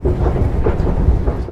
Escalator.wav